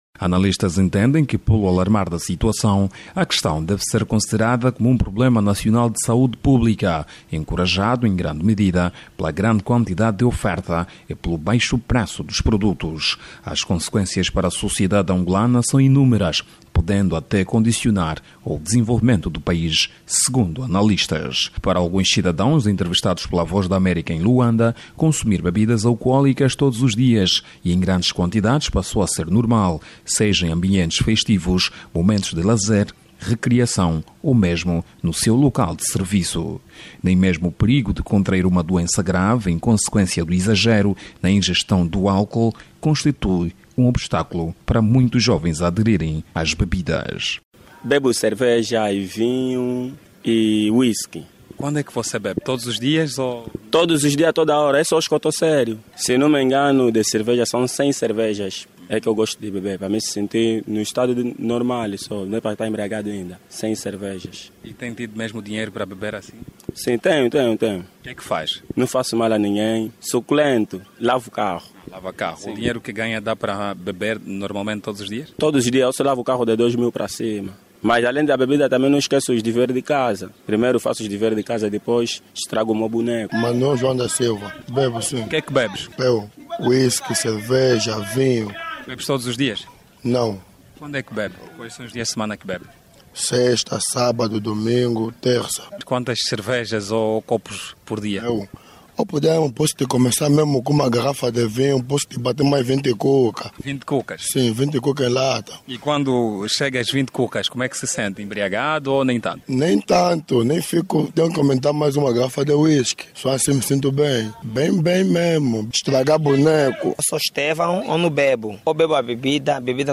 Para alguns cidadãos, entrevistados pela Voz da América em Luanda, consumir bebidas alcoólicas todos os dias e em grandes quantidades passou a ser normal.